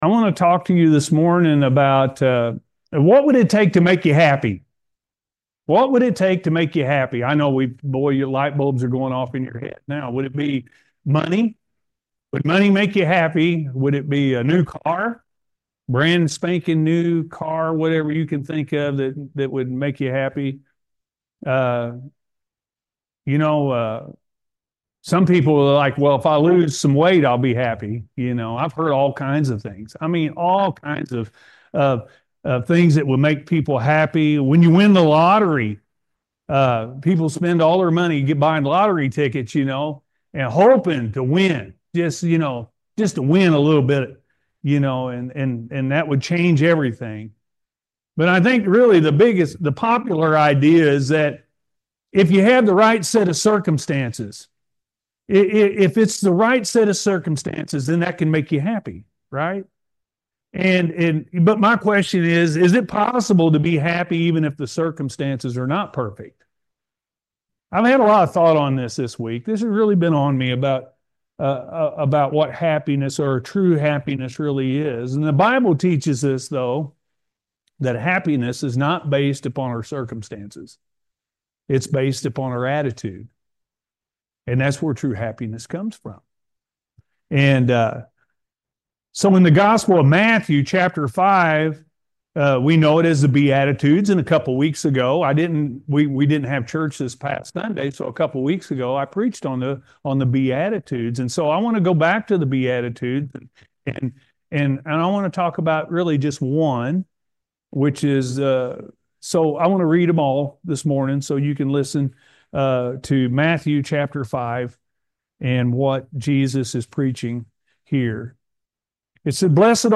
True Happiness-A.M. Service